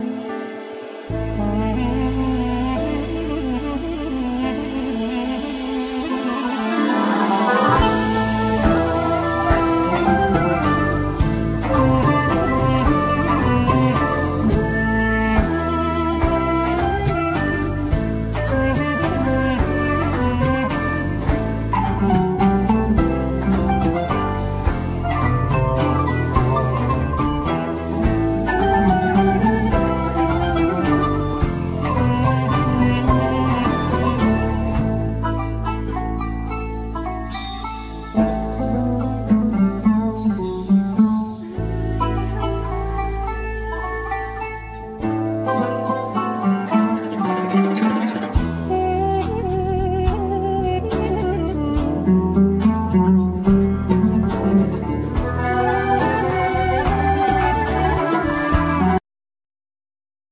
Oud
Percussions
Ney
Kanun
Violin
Duduk,Shevi